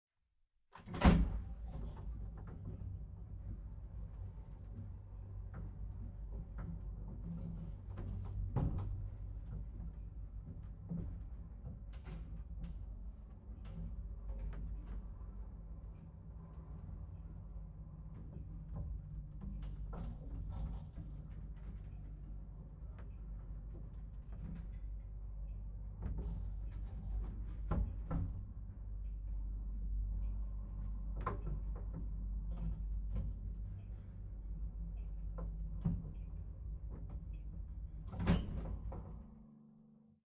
升降机 " 升降机，1960年代2，波兰，坐上去
描述：电梯，20世纪60年代2，波兰，乘坐 MS录音的立体声混音，使用Sennheiser 416（M）和8 AKG的一些小振膜。
Tag: 光滑 电梯 公寓